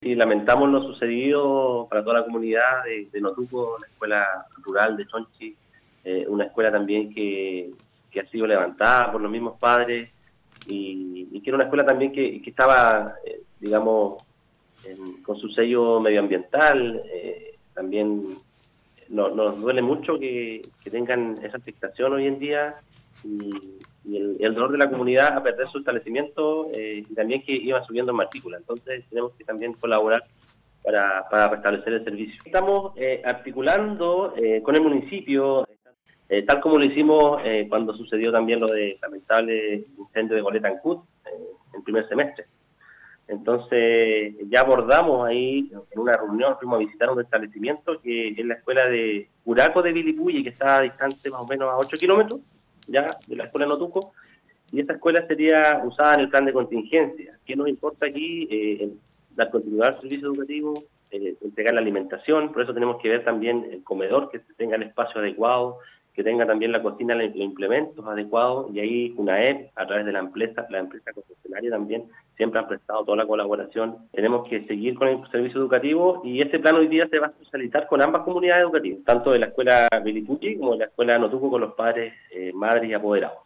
El seremi del ramo, Juan Gómez, en entrevista con radio Estrella del Mar, señaló que se trata de un hecho lamentable la pérdida de este recinto educacional pero es necesario regresar para dar término al año escolar y es por ello que se ha elegido esta solución.